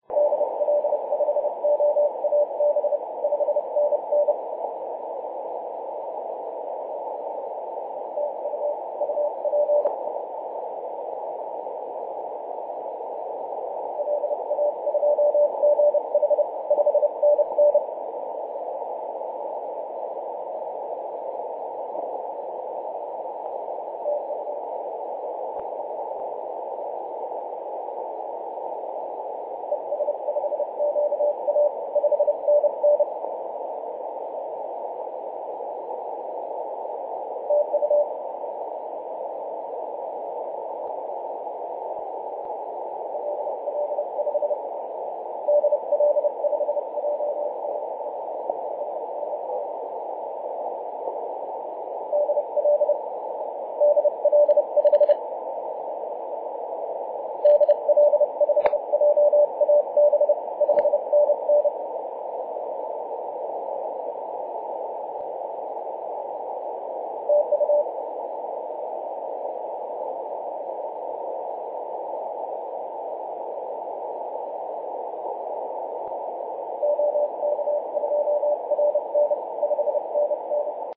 2013/Aug/20 0905z E44PM 21.026MHz CW